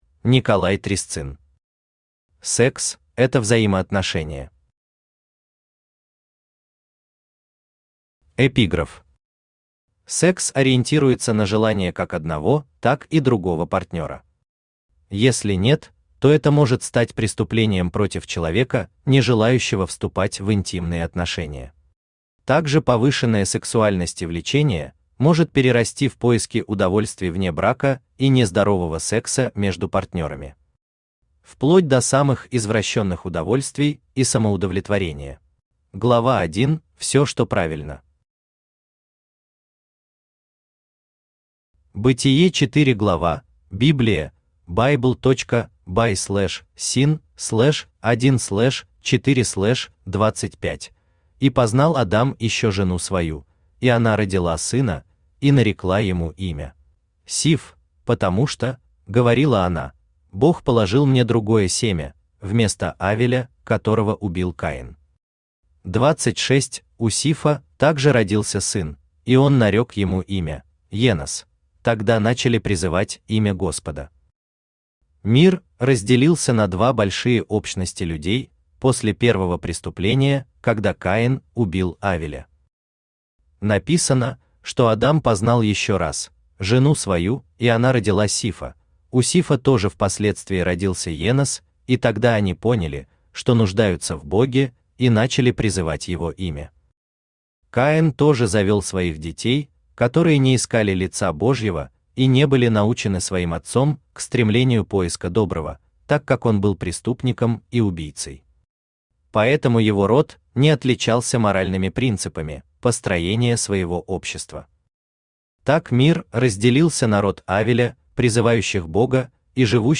Аудиокнига Секс – это взаимоотношения | Библиотека аудиокниг
Aудиокнига Секс – это взаимоотношения Автор Николай Трясцын Читает аудиокнигу Авточтец ЛитРес.